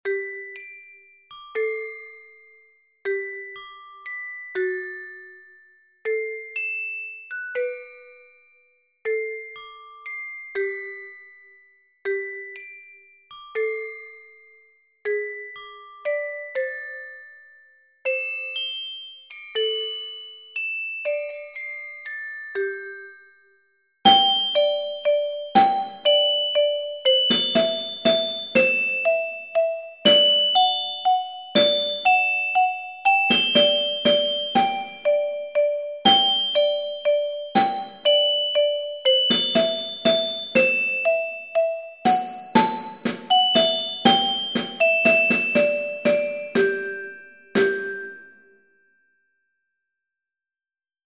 3/4 time
Percussion ensemble percussion trio